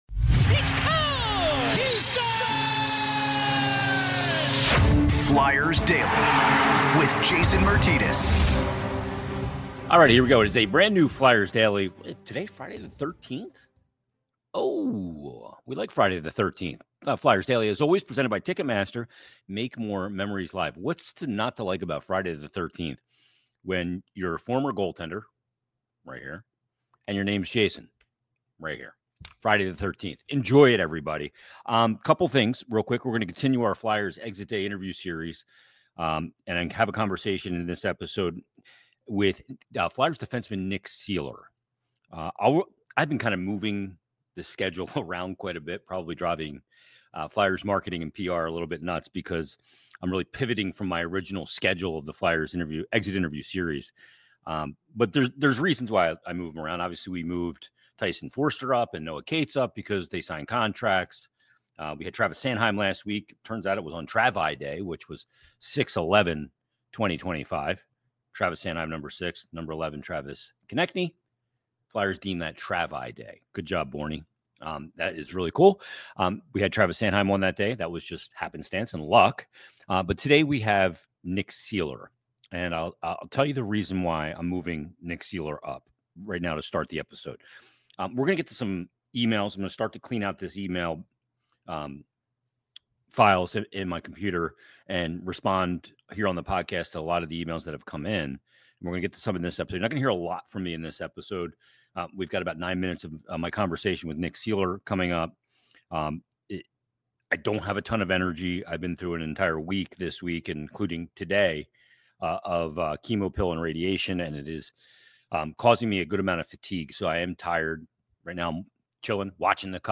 Flyers Exit Day Interview Series